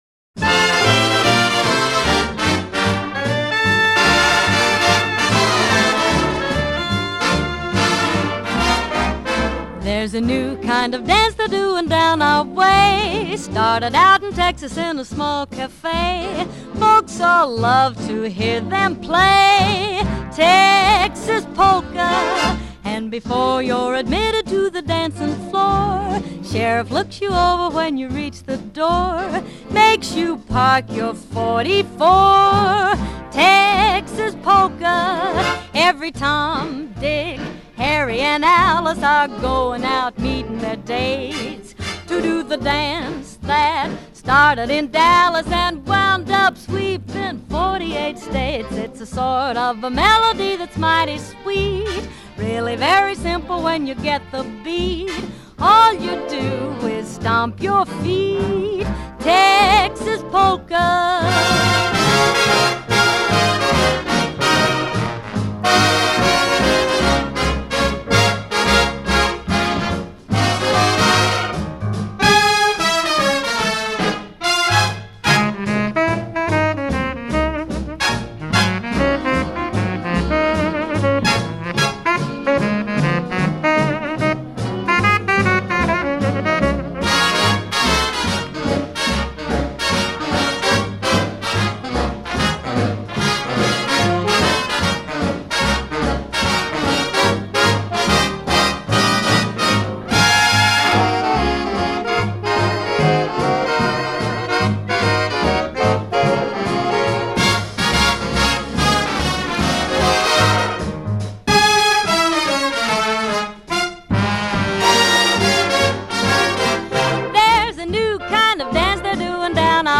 with Orchestra